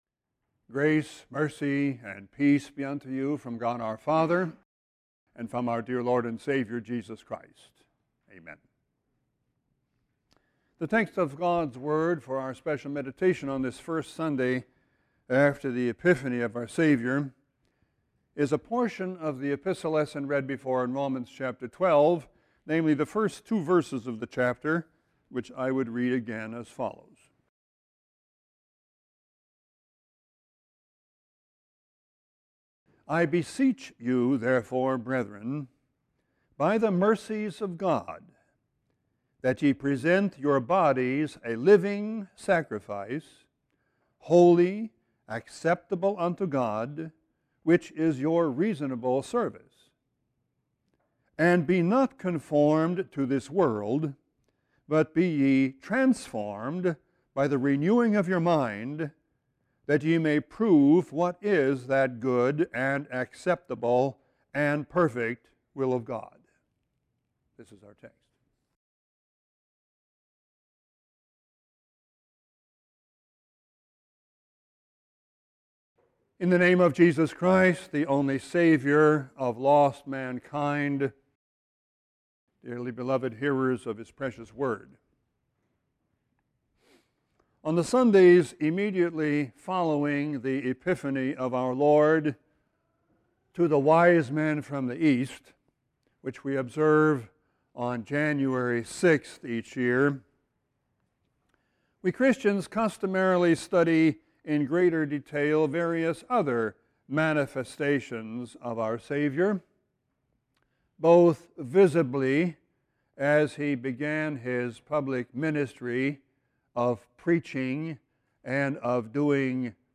Sermon 1-12-14.mp3